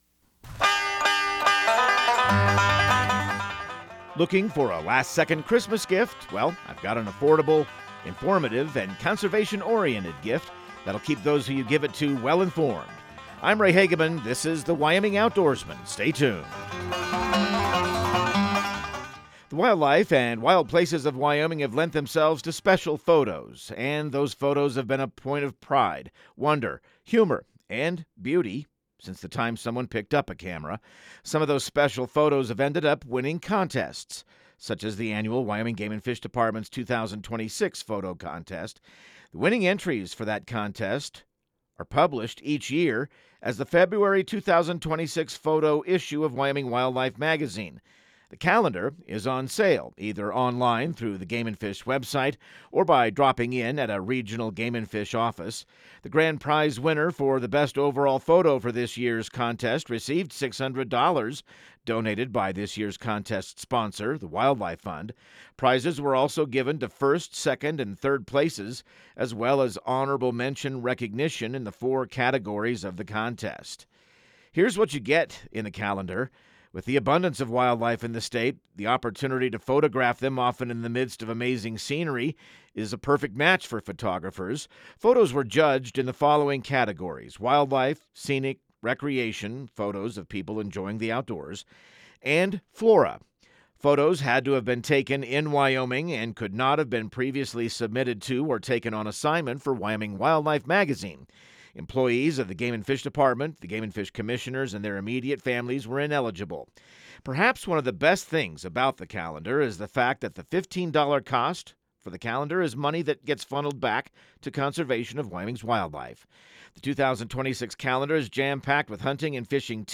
Radio news | Week of December 22